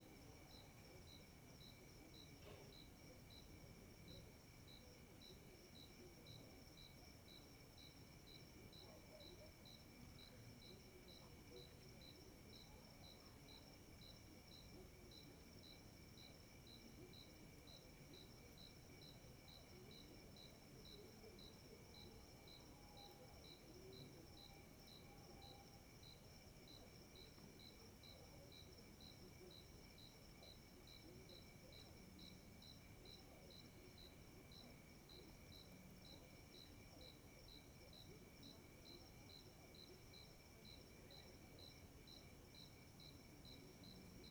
Ambiencia engenho a noite com grilo, morcego e tv longe Animais , Engenho , Grilo , Insetos , Morcego , Noite , Rural , TV Cavalcante Stereo
CSC-03-010-LE - Ambiencia engenho a noite com grilo, morcego e tv longe.wav